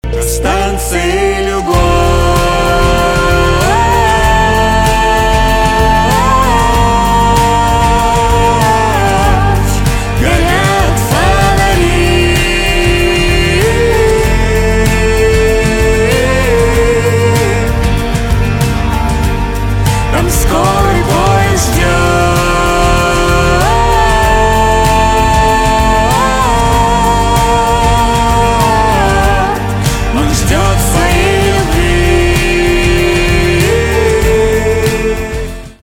инди
гитара , барабаны
чувственные